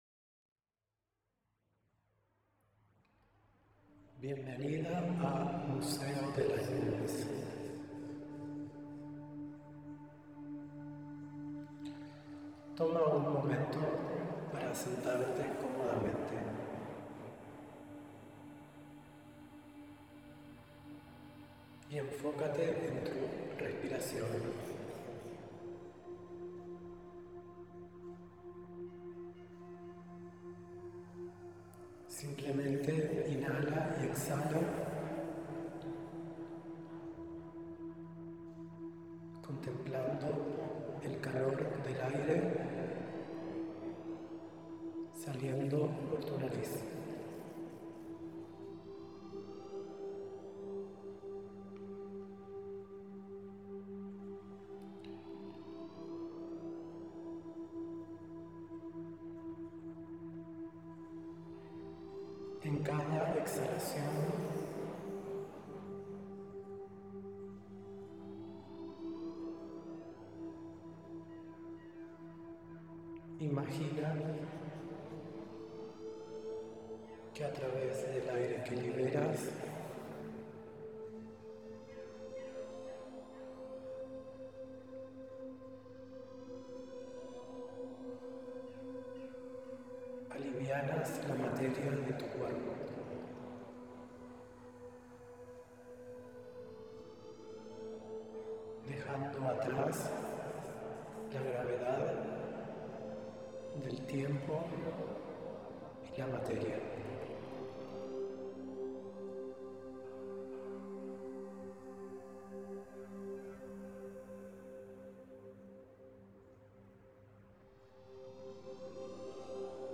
meditacion-Cloud-Museum-1.mp3